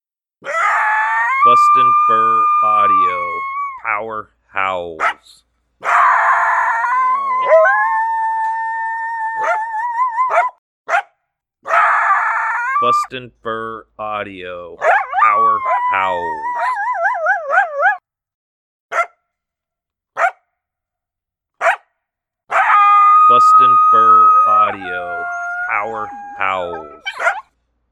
BFA's Apollo and Beans Howling together with intensity and authority. This sound is one the wild Coyotes will perceive as trespassers on their turf and respond accordingly.